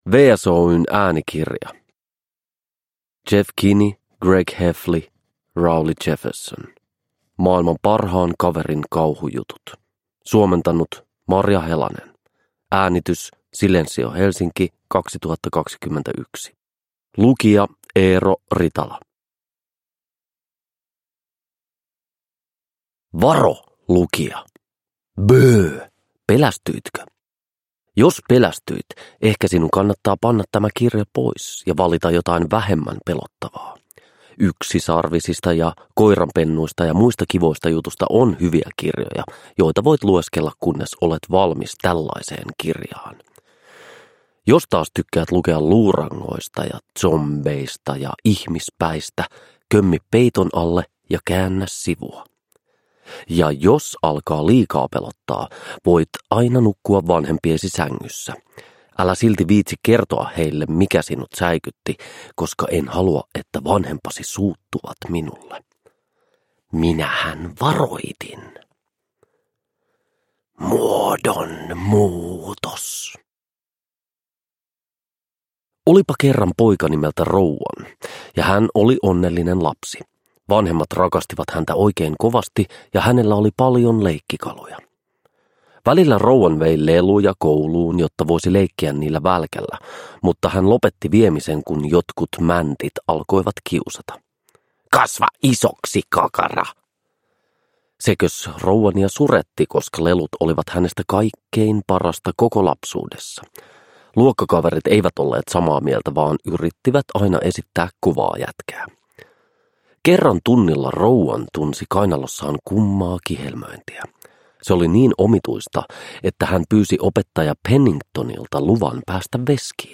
Maailman parhaan kaverin kauhujutut. Kirjoittanut Rowley Jefferson – Ljudbok – Laddas ner